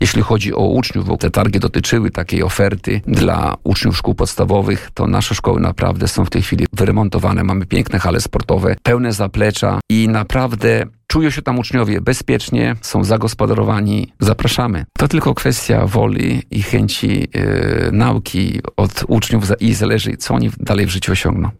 Według starosty kolneńskiego Tadeusza Klamy, szkoły prowadzone przez powiat mają wiele atutów, by przekonać do siebie młodzież. Samorządowiec mówił na naszej antenie, że powiatowe placówki oferują potencjalnym uczniom wysokiej jakości infrastrukturę.